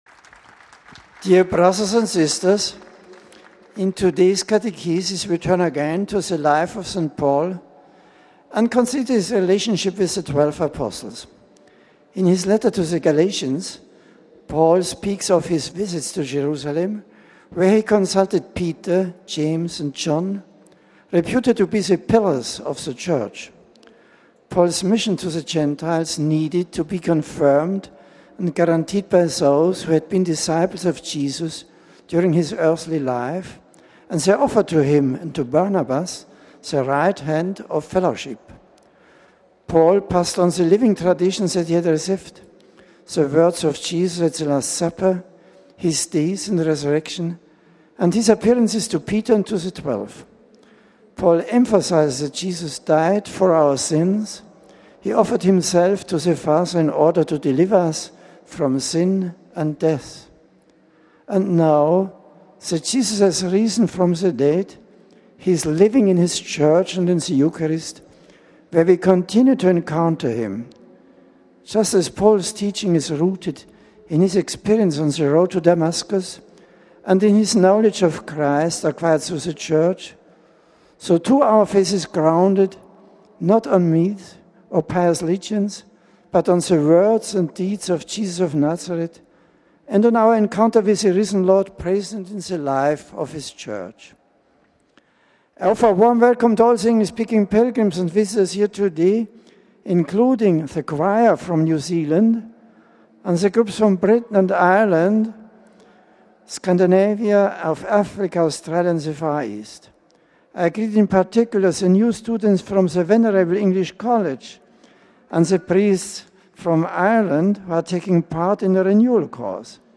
(September 24, 2008) Pope Benedict XVI on Wednesday held his weekly General Audience in the open in St. Peter’s Square in Rome. In keeping with the current Pauline Jubilee Year, the Pope in his is discourse resumed his reflection on St. Paul.